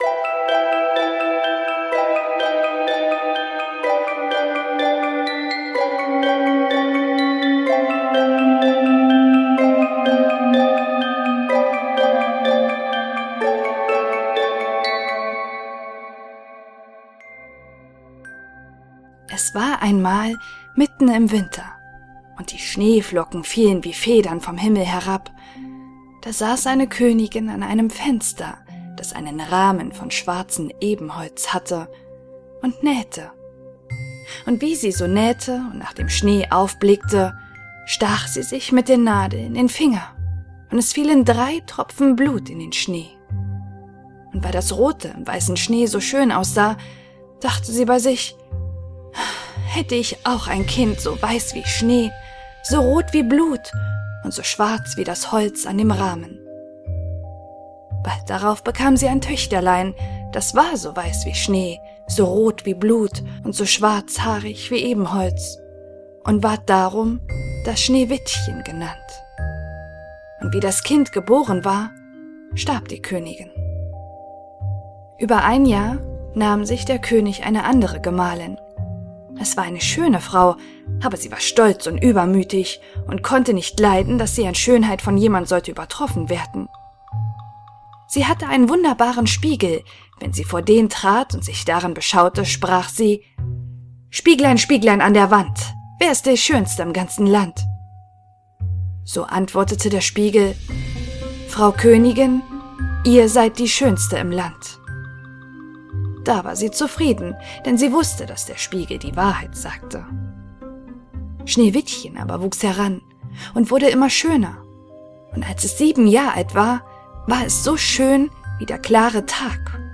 Schneewittchen: Grimms schönstes Märchen in der reinsten Hörbuch-Version